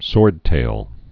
(sôrdtāl)